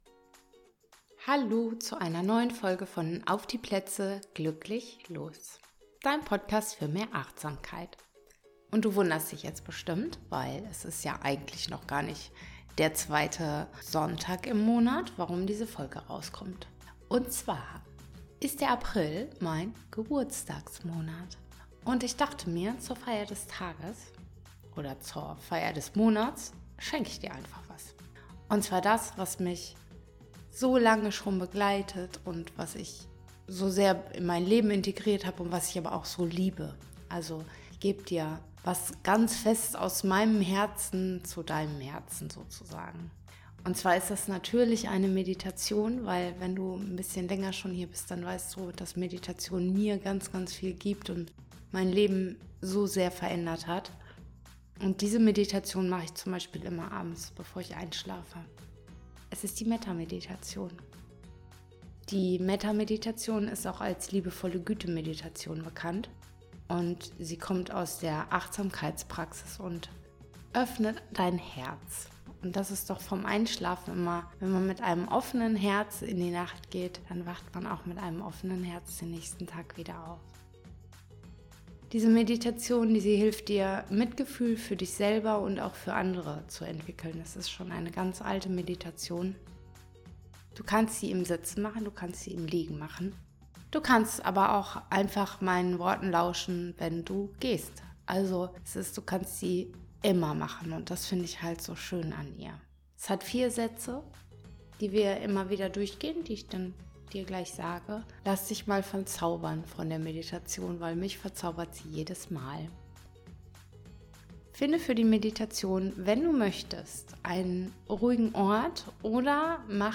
In meinem Podcast teile ich eine geführte Metta-Meditation, die dich einlädt,– dich selbst anzunehmen, wie du gerade bist– anderen mit Mitgefühl zu begegnen– einen weichen, achtsamen Raum in dir zu öffnen.